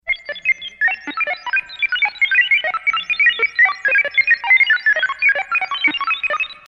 Satellite Noise.mp3